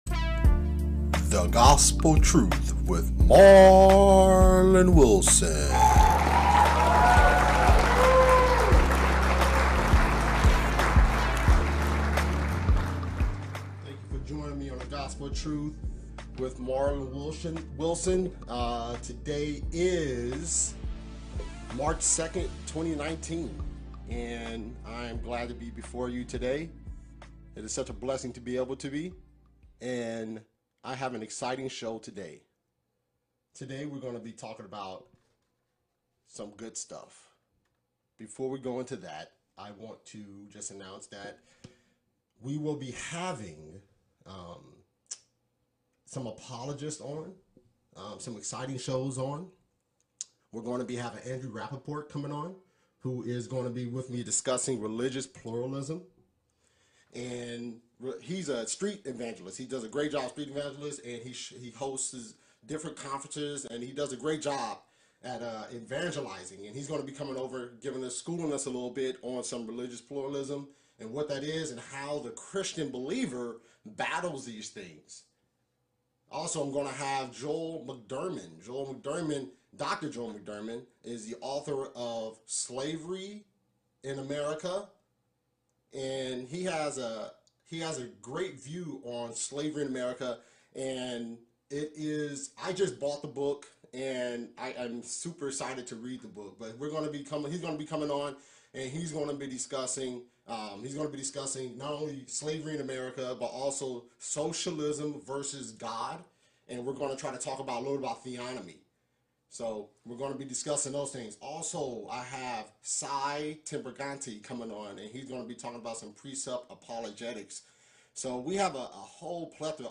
Interview: Racial/Political Divide in the Church